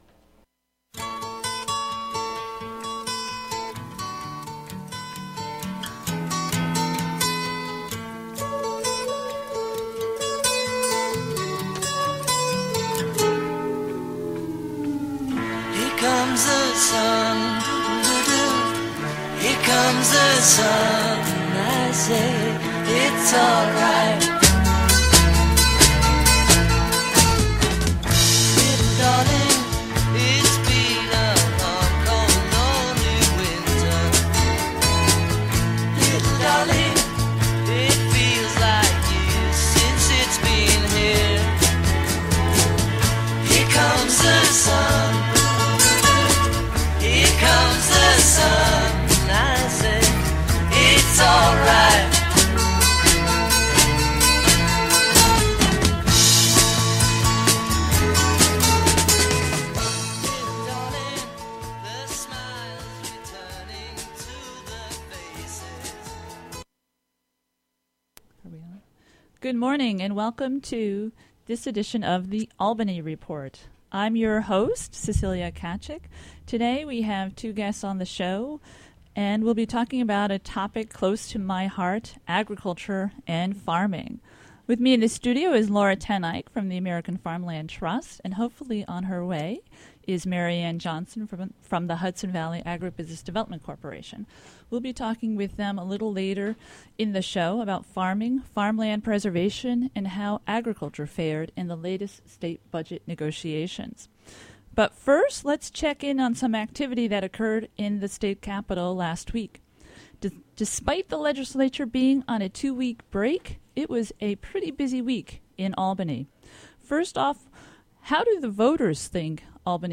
Hosted by Cecilia Tkaczyk.